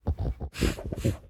Minecraft Version Minecraft Version latest Latest Release | Latest Snapshot latest / assets / minecraft / sounds / mob / sniffer / searching3.ogg Compare With Compare With Latest Release | Latest Snapshot